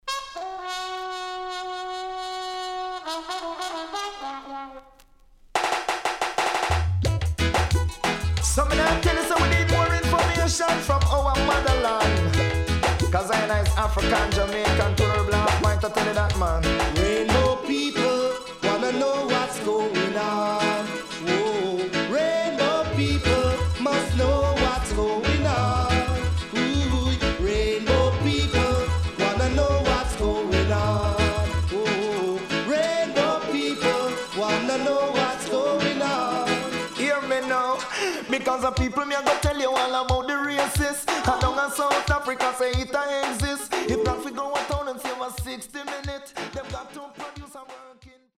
【12inch】
マイナー調
SIDE A:少しチリノイズ入ります。